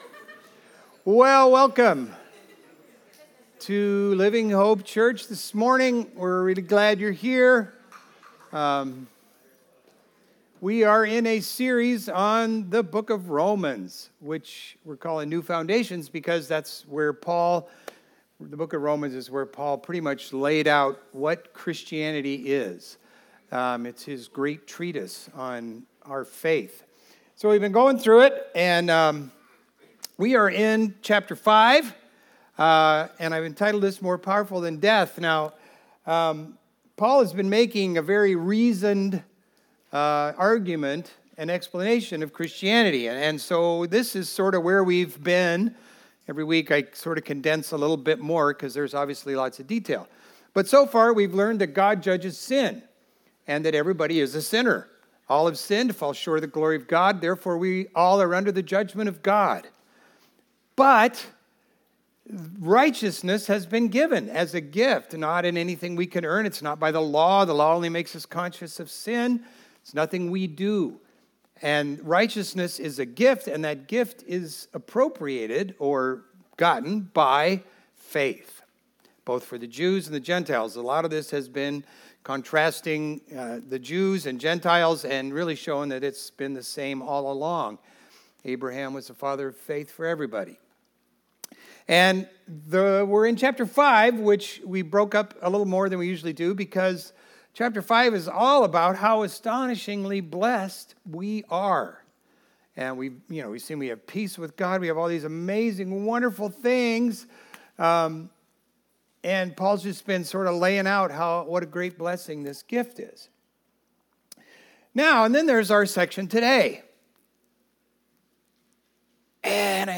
Video Audio Download Audio Home Resources Sermons More Powerful Than Death Jul 27 More Powerful Than Death Paul explains how the gift of righteousness in Christ is greater than the curse of death.